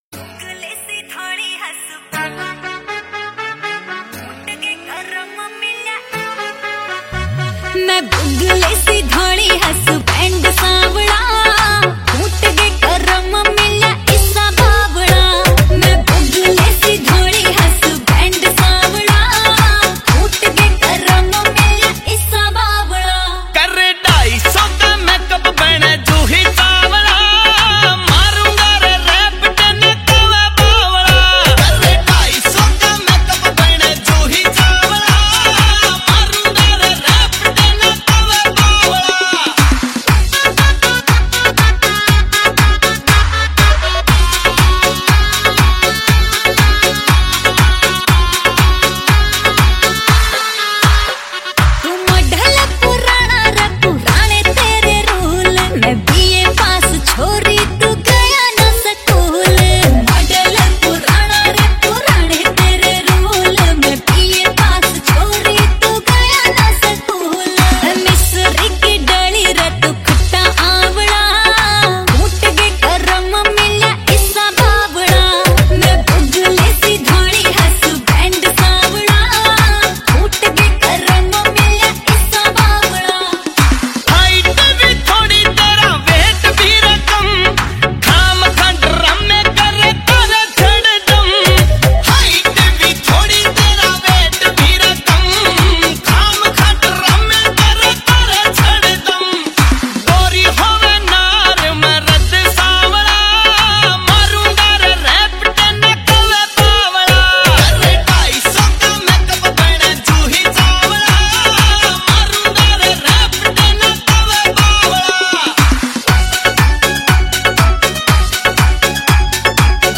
Category New Haryanvi Song 2023 Singer(s